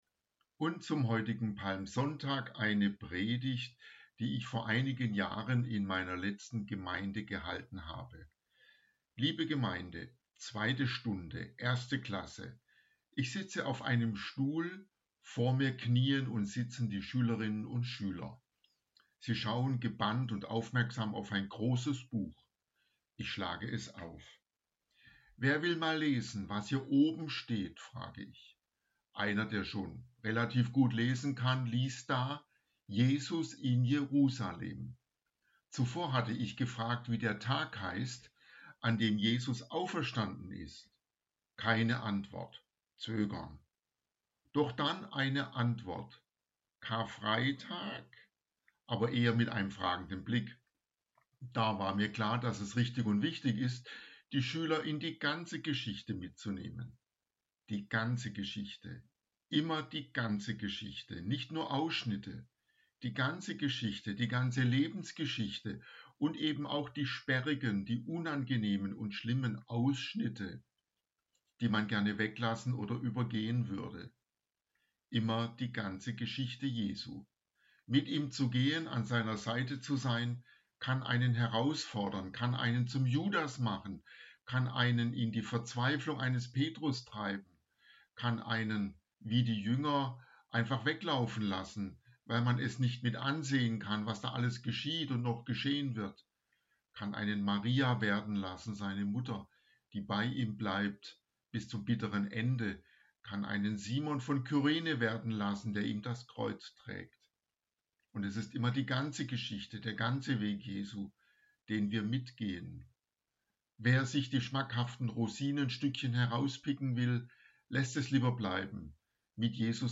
Predigt zum Palmsonntag